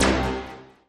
Appear_Scatter_Sound.mp3